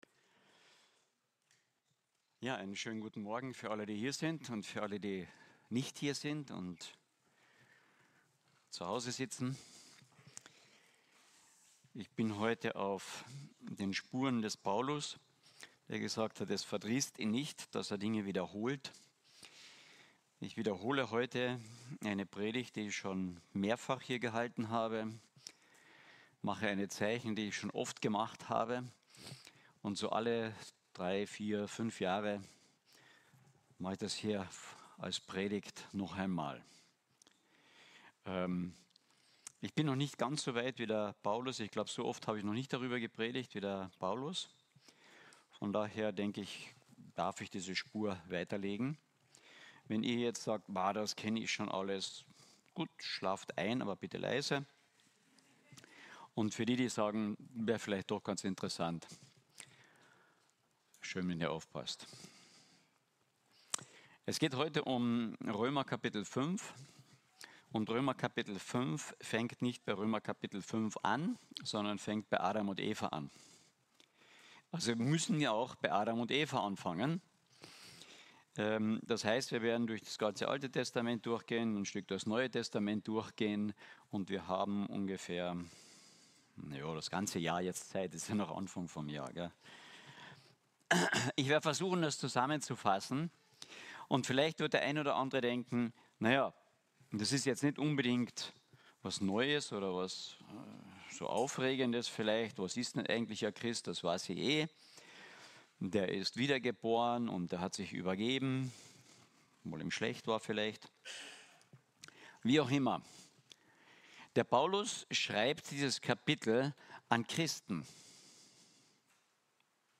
Eine predigt aus der serie "Einzelpredigten 2025."